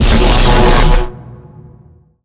point_blowout.ogg